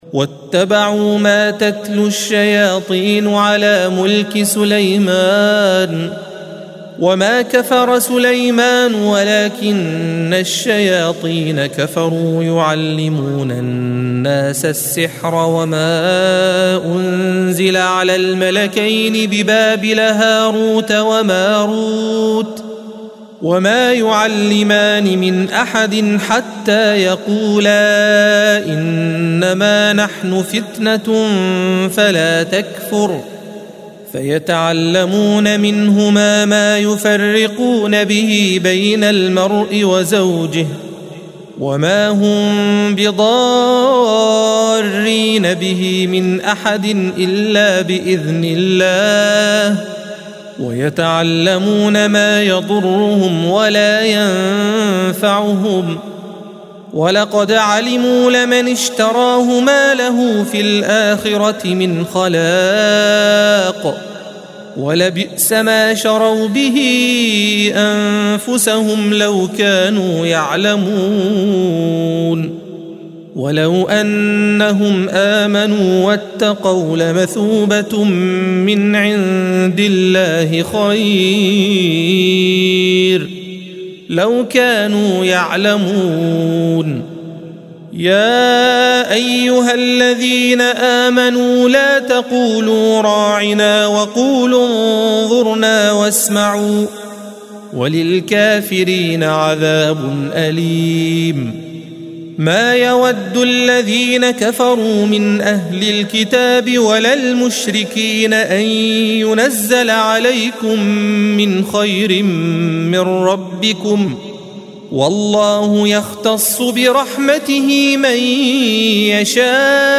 الصفحة 16 - القارئ